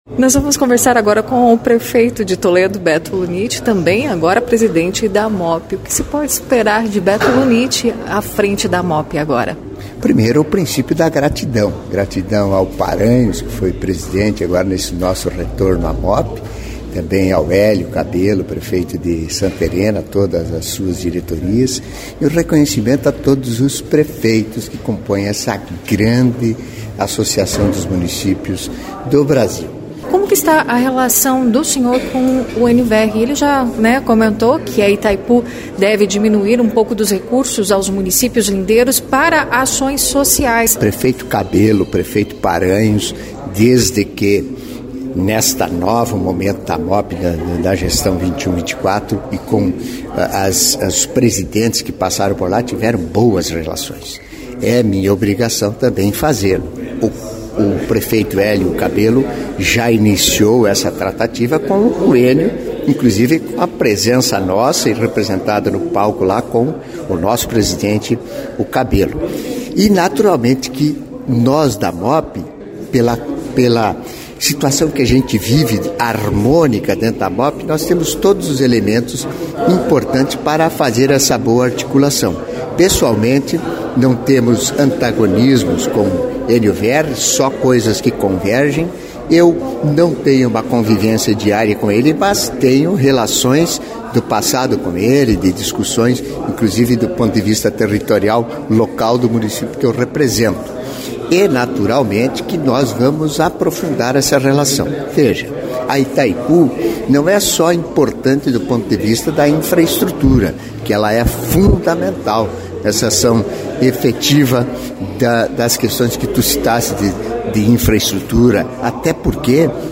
A Associação dos Municípios do Oeste do Paraná (Amop) realizou nesta sexta-feira (23) a 2ª Assembleia Ordinária de 2023 com a eleição e posse da nova diretoria da entidade.
Player Ouça prefeito de Toledo e presidente da Amop Beto Lunitti